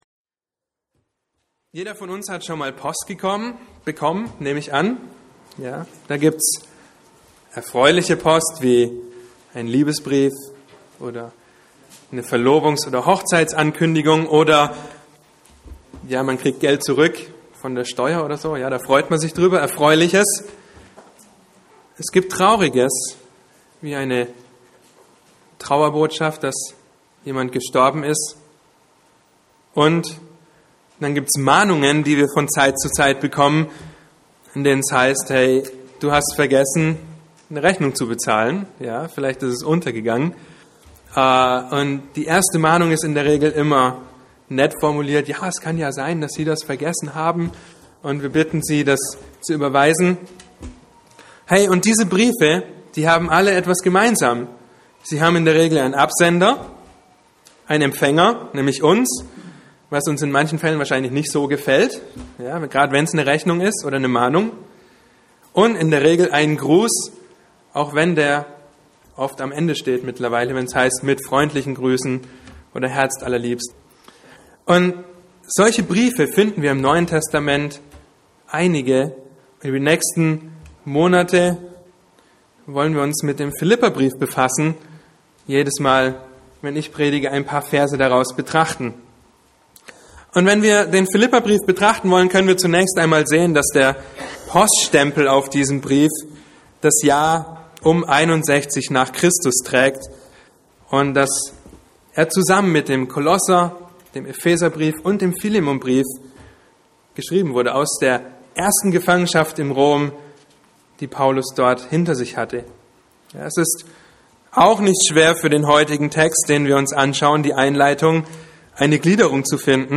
Mit dieser Predigtserie betrachten wir in einer Vers-für-Vers Auslegung den Philipperbrief.